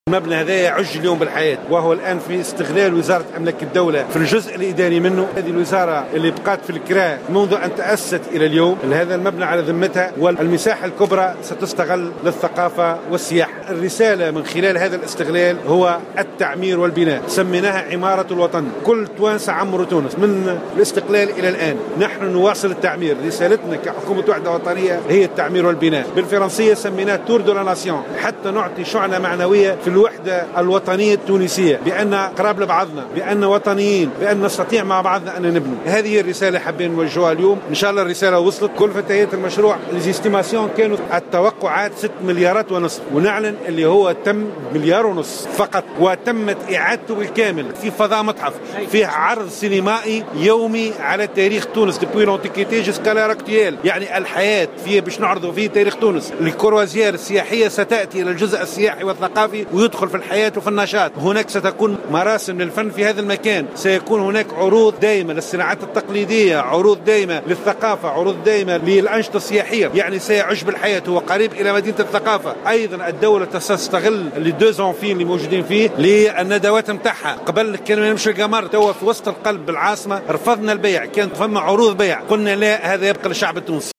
أكد كاتب الدولة لأملاك الدولة و الشؤون العقارية مبروك كرشيد في تصريح لمراسل الجوهرة "اف ام" اليوم الثلاثاء 25 أفريل 2017 أن مبنى دار التجمع سيكون في الجزء الإداري منه تحت ذمة وزارة أملاك الدولة أما الجزء الأكبر فسيستغل لوزارتي الثقافة والسياحة .